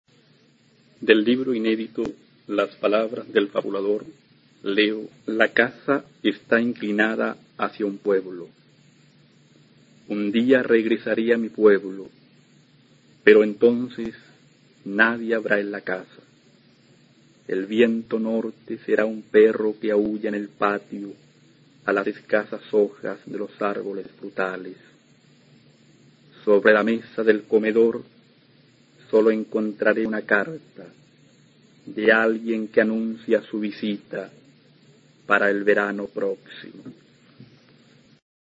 Poema
Lírica